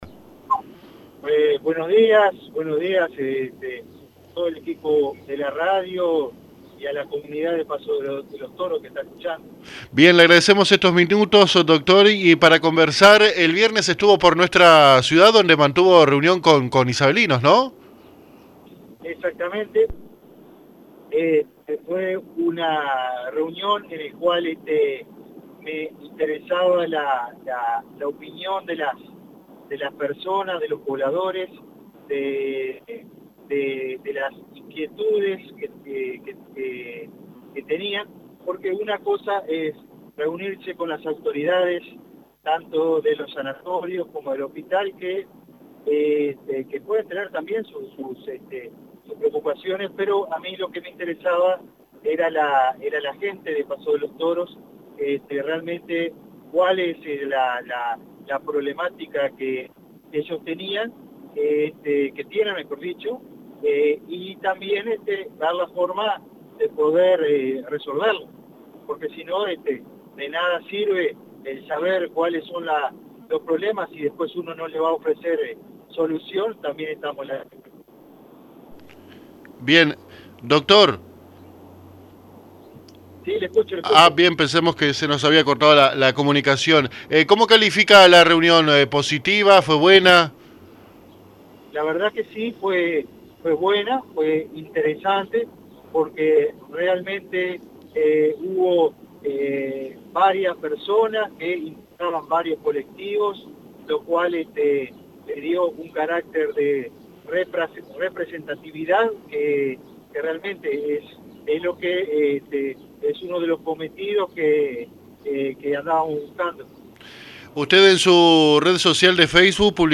El Director Departamental de Salud, Dr, Juan Motta, se expresó a AM 1110 sobre la reunión que tuvo el pasado viernes 3 con los vecinos y representantes de usuarios de nuestra ciudad, «Fue una reunión en la que interesaba la opinión de las personas, de las inquietudes que tenían», comentó, agregando que dicha instancia fue buena e interesante.